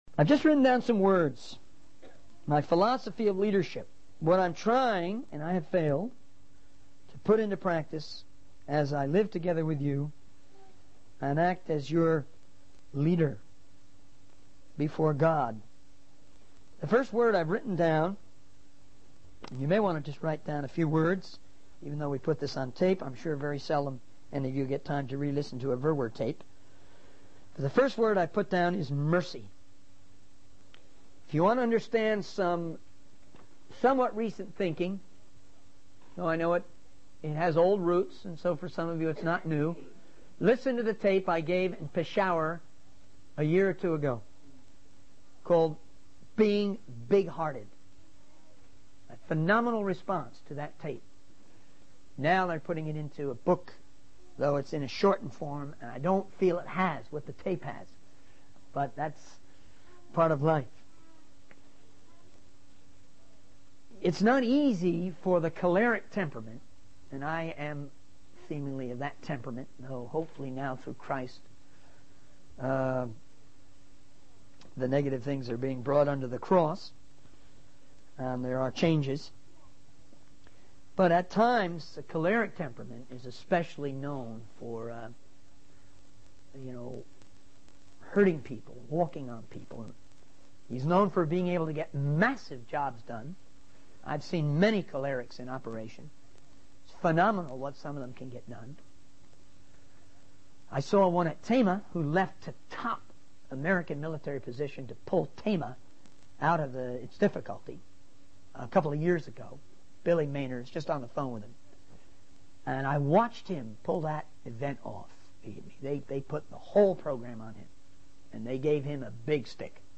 In this sermon, the speaker shares his philosophy of leadership and the importance of forgiveness. He emphasizes the need for mercy and how it is rooted in God's love and the sacrifice of Jesus on the cross.